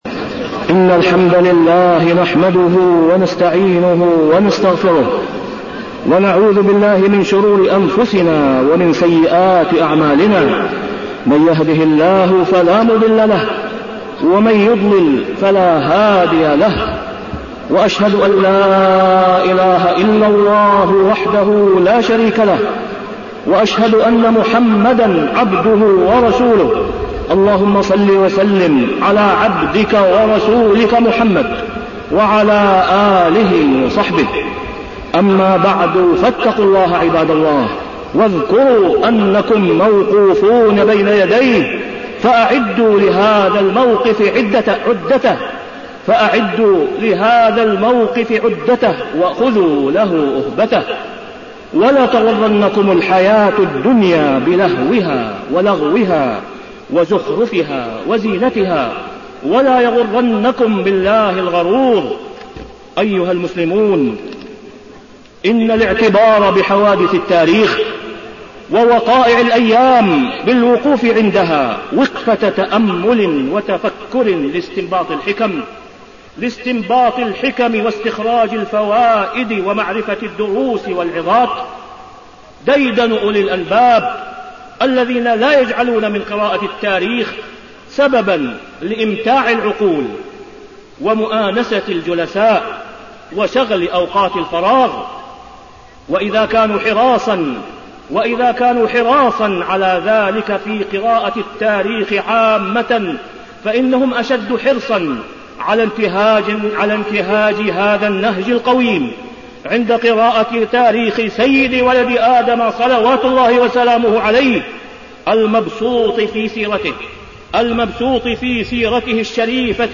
تاريخ النشر ٢٨ ذو الحجة ١٤٢٩ هـ المكان: المسجد الحرام الشيخ: فضيلة الشيخ د. أسامة بن عبدالله خياط فضيلة الشيخ د. أسامة بن عبدالله خياط الهجرة النبوية